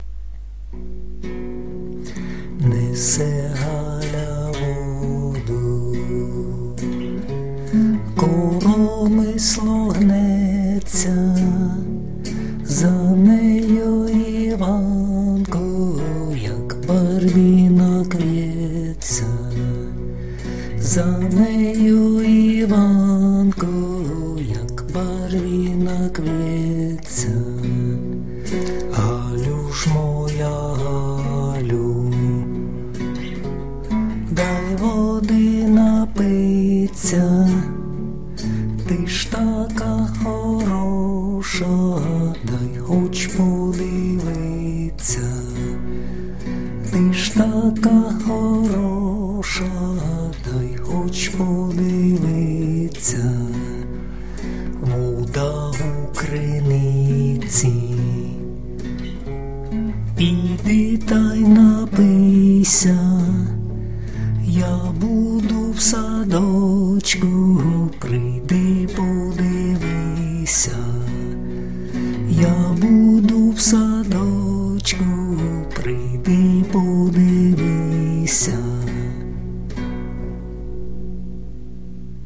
../icons/nichnevi.jpg   Українська народна пiсня